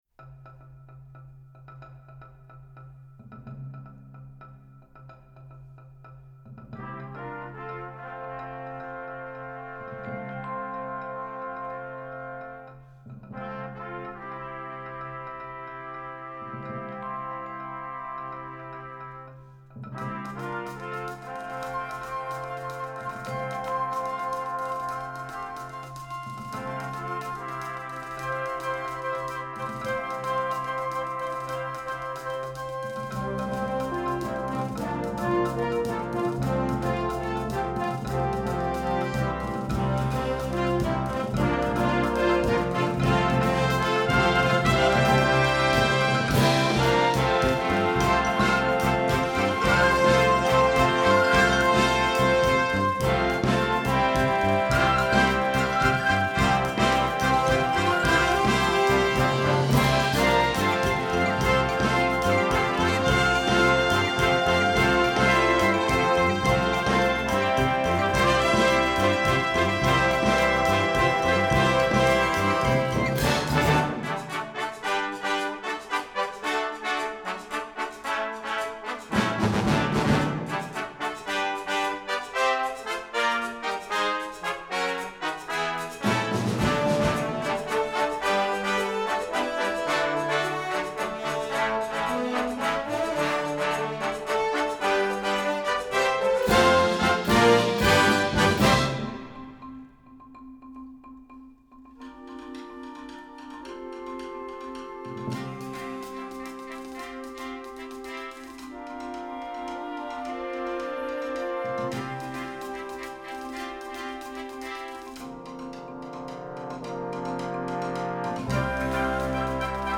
LIGHT CONCERT